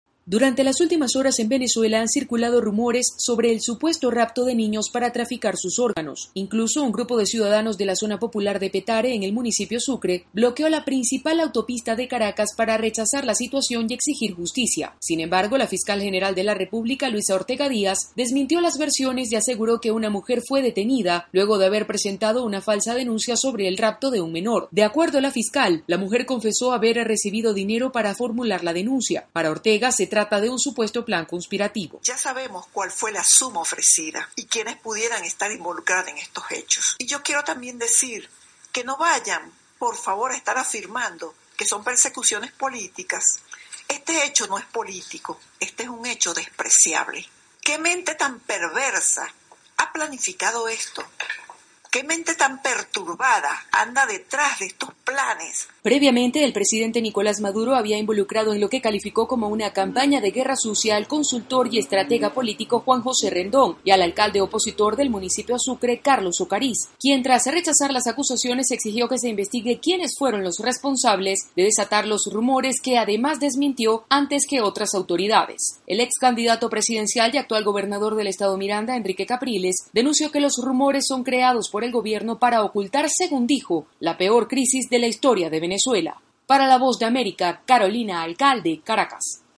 Miembros de la oposición venezolana aseguran que el gobierno de Nicolás Maduro propicia rumores para ocultar la crisis que vive el país. Desde Caracas